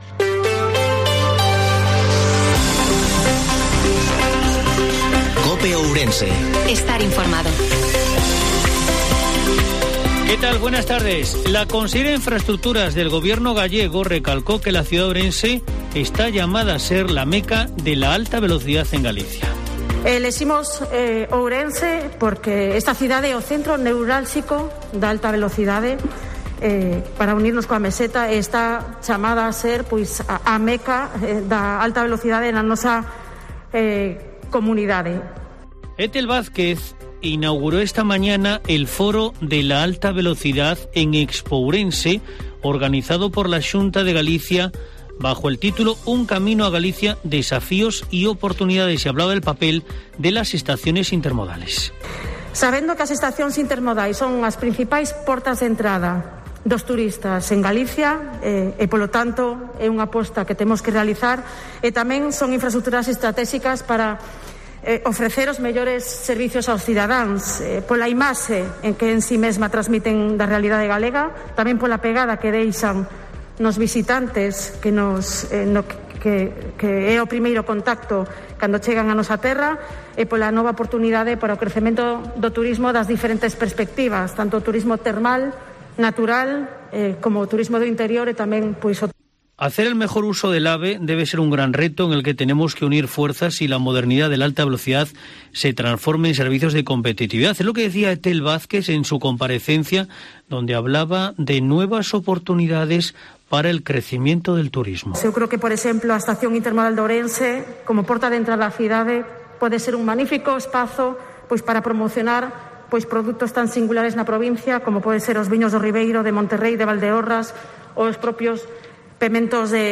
INFORMATIVO MEDIODIA COPE OURENSE